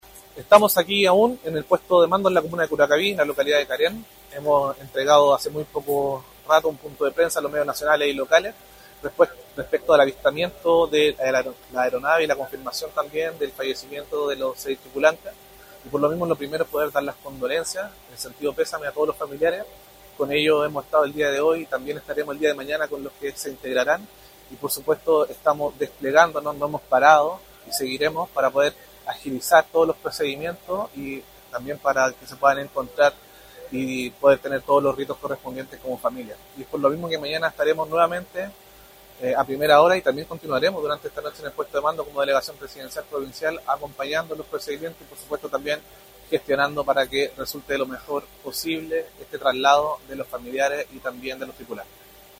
Por su parte, el delegado presidencial provincial de Melipilla, Bastián Alarcón, destacó el trabajo coordinado desde las primeras horas de la emergencia, señalando que desde la tarde del miércoles se mantuvieron desplegados en terreno junto a los equipos de emergencia e instituciones del Estado, enfocados en atender la situación y colaborar en la búsqueda de la aeronave.